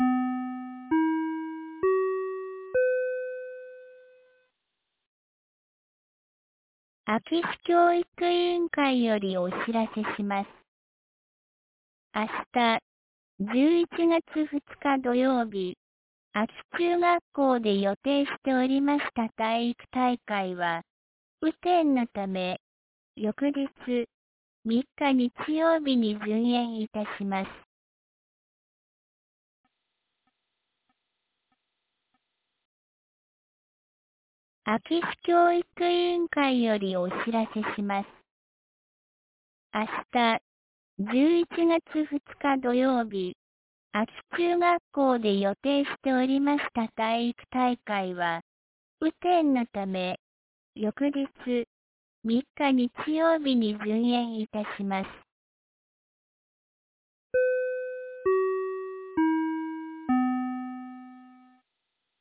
2024年11月01日 17時11分に、安芸市より全地区へ放送がありました。